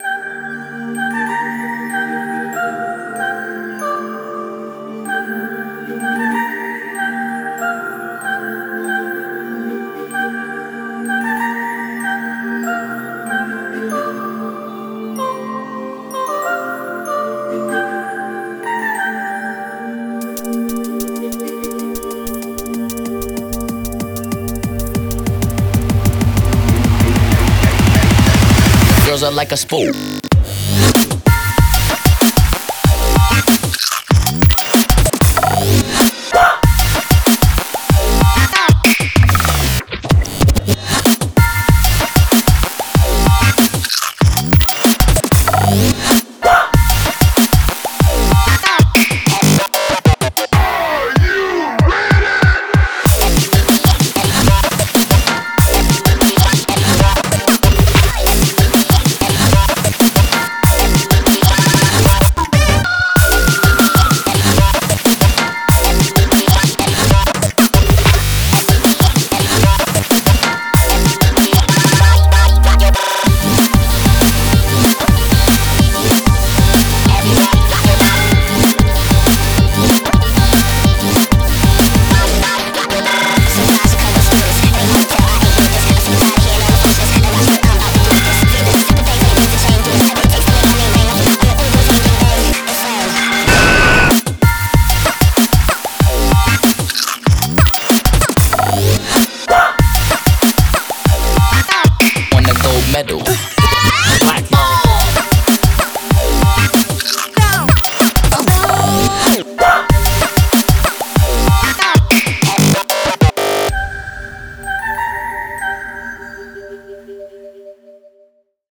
BPM48-190